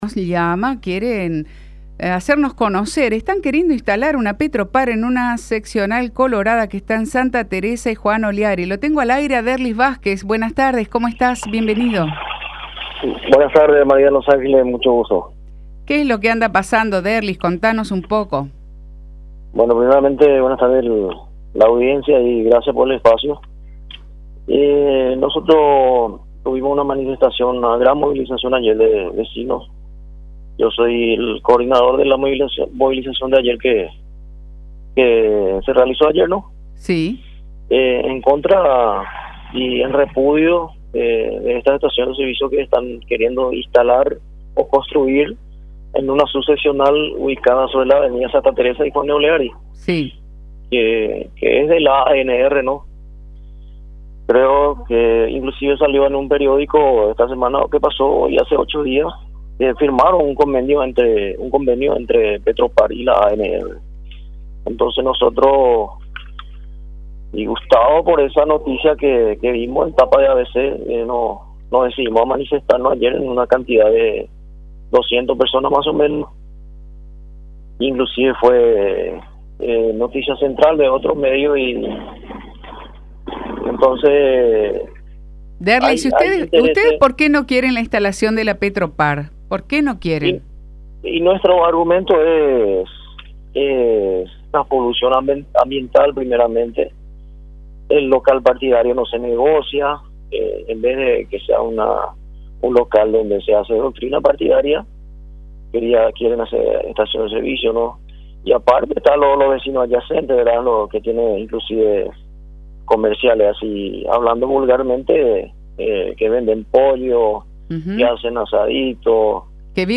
habló en contacto con La Unión R800 AM con respecto a esta marcha realizada ayer contra una construcción de estación de servicios Petropar en el predio de una subseccional